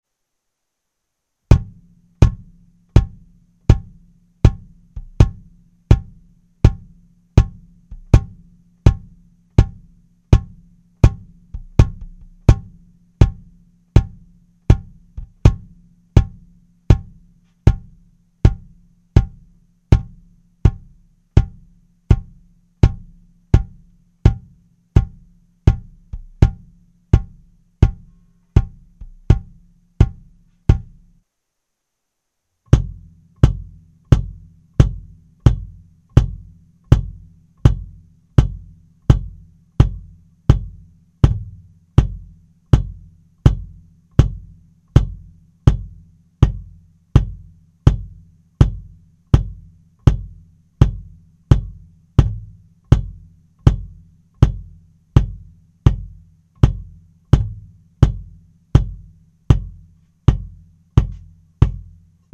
Bassdrumvergleich: Acryl oder Birkenholz?
Daraus ergibt sich, dass aus dem Birkenholz in 22x16 Acryl in 22x18 geworden ist. Was wie klingt, könnt ihr hören: Bassdrummikro Audio Technica Pro 26 Ihr habt eine 50% Chance, die richgtige zu erraten, aber vielleicht hört es ja auch jemand. Mit Overhead Beyerdynamic MTG 201